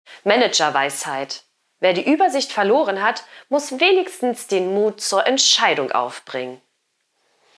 Vorgetragen von unseren attraktiven SchauspielerInnen.
Comedy , Unterhaltung , Kunst & Unterhaltung